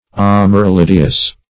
Search Result for " amaryllideous" : The Collaborative International Dictionary of English v.0.48: Amaryllidaceous \Am`a*ryl`li*da"ceous\, Amaryllideous \Am`a*ryl*lid"e*ous\, a. (Bot.)